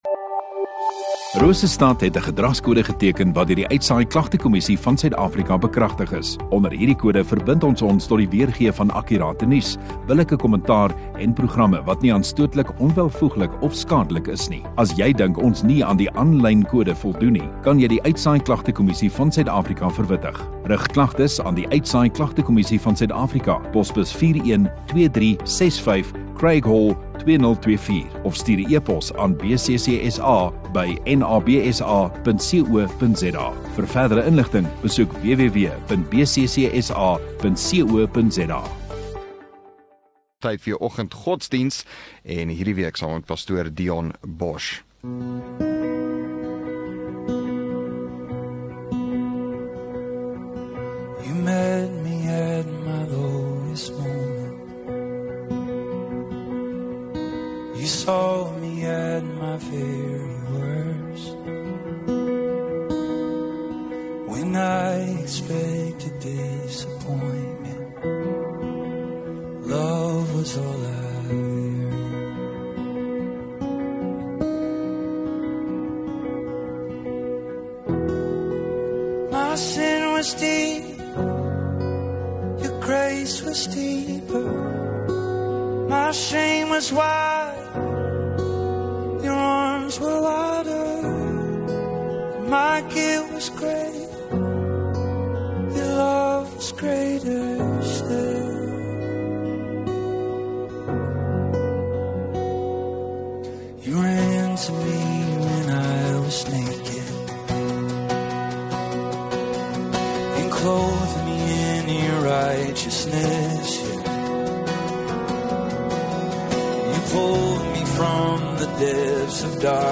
8 Apr Dinsdag Oggenddiens